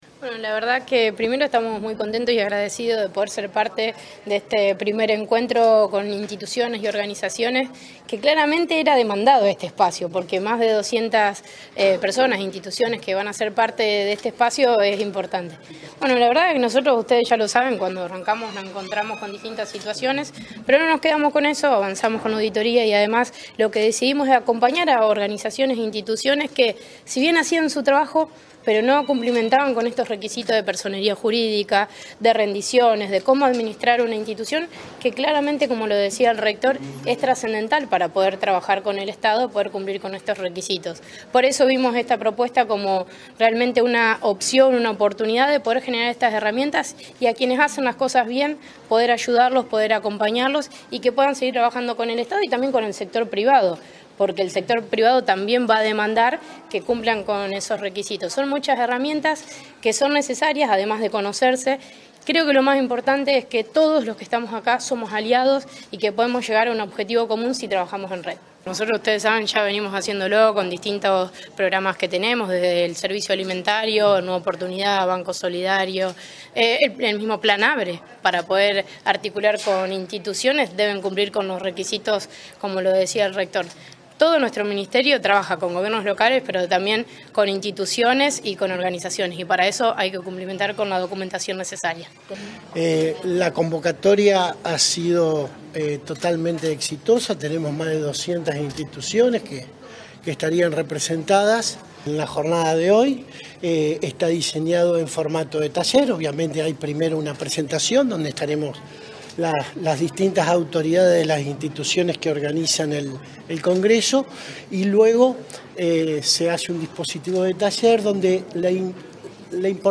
Más de 200 entidades de la región participaron del Primer Congreso Santafesino de Organizaciones de la Sociedad Civil
Declaraciones de Tejeda